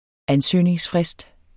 Udtale [ ˈanˌsøjˀneŋs- ]